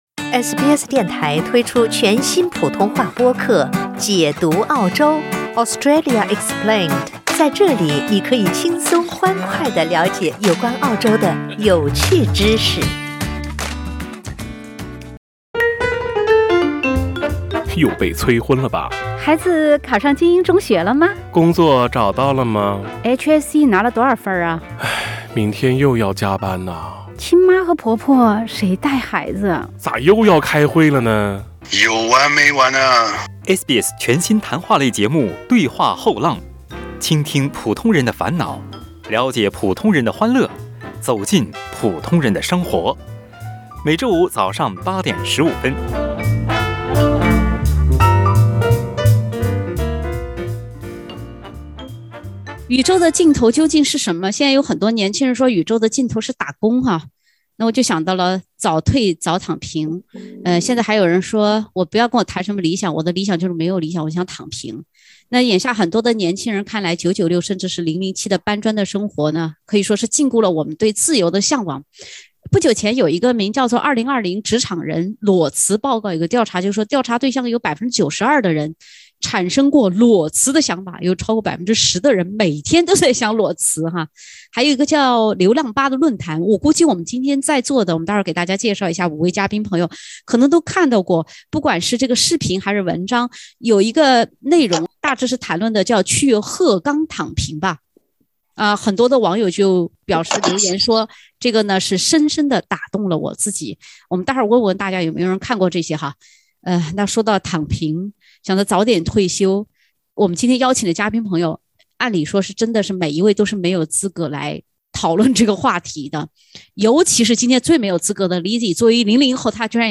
就算在澳洲为什么现在也有如此多的人盼着能早退早躺平？（点击封面图片，收听有趣对话）